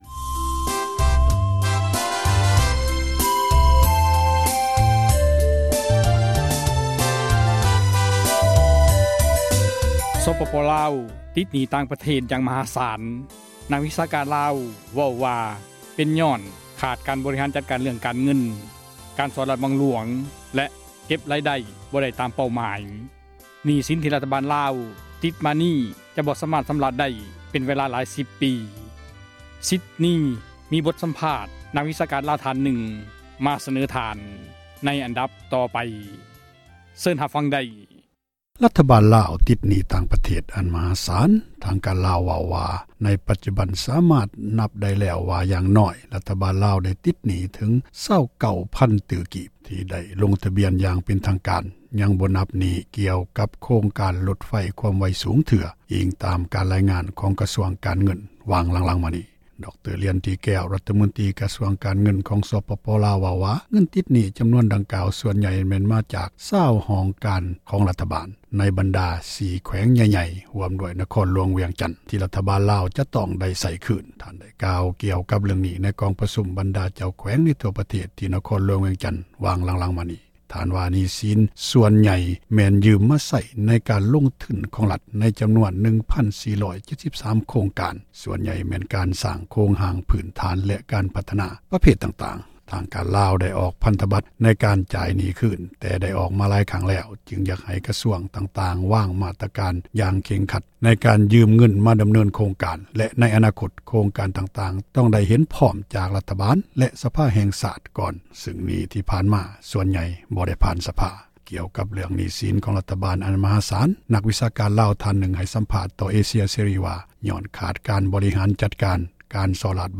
ກ່ຽວກັບ ເຣື້ອງ ຫນີ້ສິນ ຂອງ ຣັຖບານ ອັນ ມະຫາສານ ນັກ ວິຊາການ ລາວ ທ່ານນຶ່ງ ໃຫ້ ສຳພາດ ຕໍ່ ເອເຊັຽ ເສຣີ ວ່າ ຍ້ອນຂາດ ການ ບໍຣິຫານ ຈັດການ ການສໍ້ຣາສ ບັງຫຼວງ ແລະ ການ ຮົ່ວໄຫລ ຂອງ ຣາຍໄດ້ ຂອງຣັຖ ນັ້ນເອງ ດັ່ງ ທ່ານ ໃຫ້ ສຳພາດ ວ່າ: